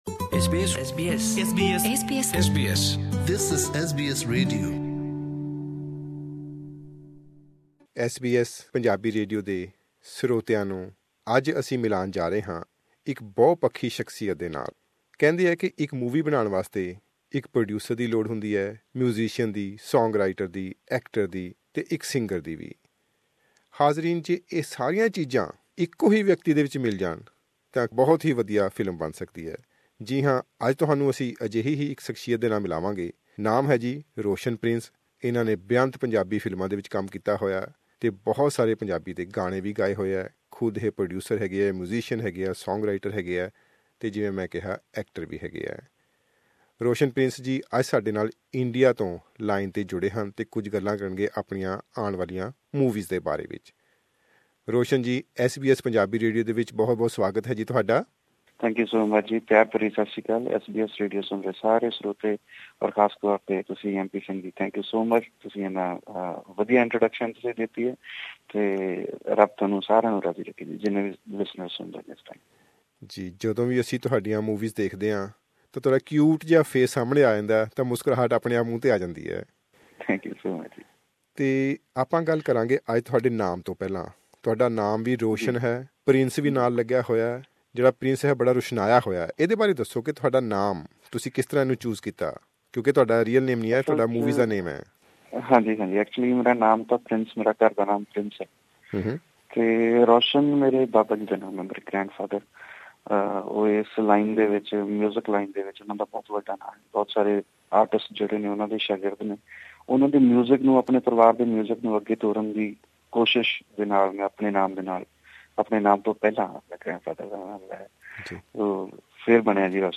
Roshan Prince, in this exclusive interview with SBS Punjabi talks about his latest comedy film 'Lavaan Phere', in which he is a distraught Saala with three typical Jijjas, and all creating so many scenes during his wedding.